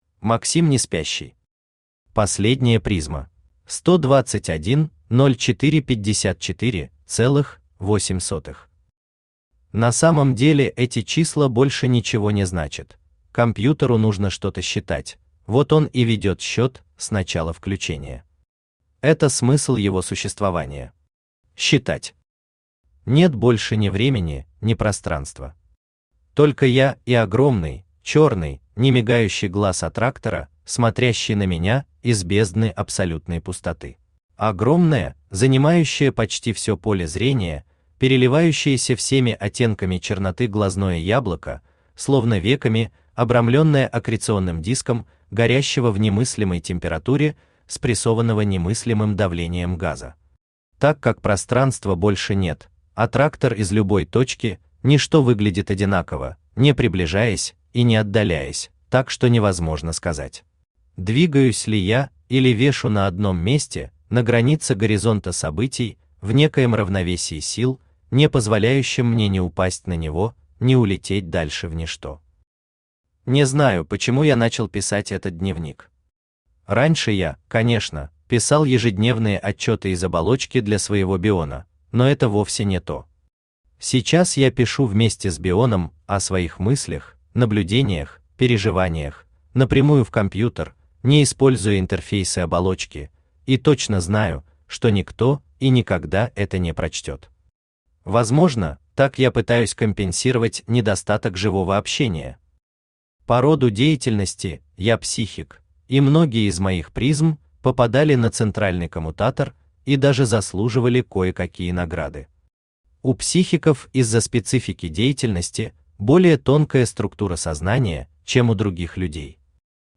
Аудиокнига Последняя призма | Библиотека аудиокниг
Aудиокнига Последняя призма Автор Максим Олегович Неспящий Читает аудиокнигу Авточтец ЛитРес.